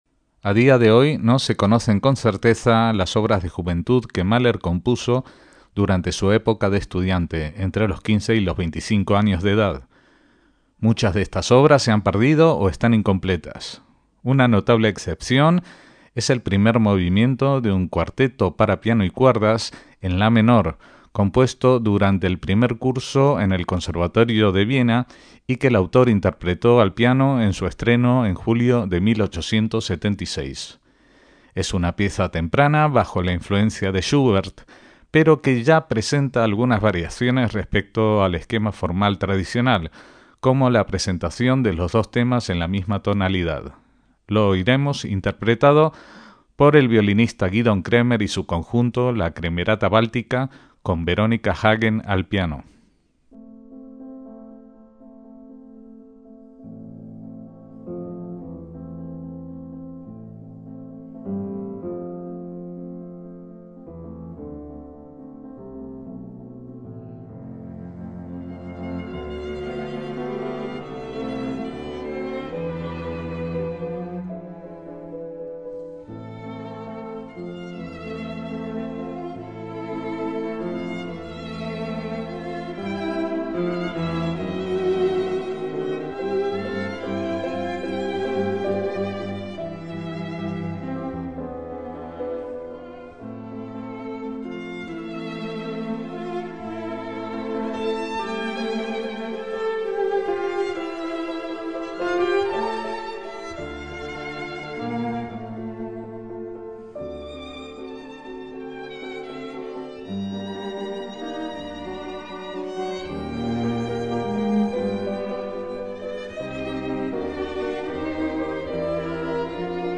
El Cuarteto para piano y cuerdas en la menor, con Gidon Kremer y la Kremerata Báltica